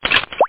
ploop1.mp3